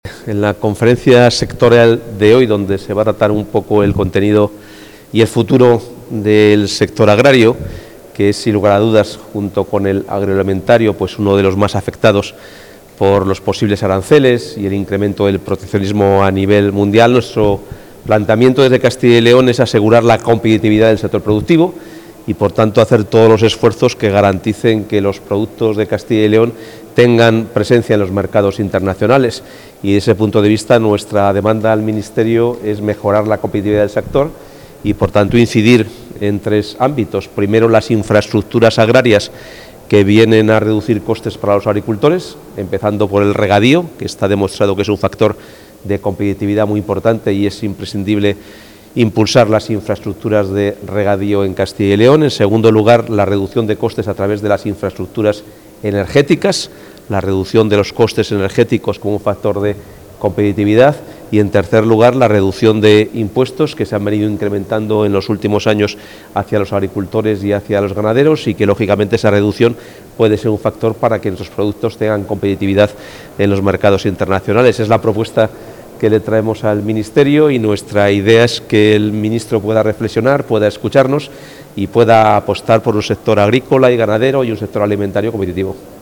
Declaraciones del consejero previas a la reunión.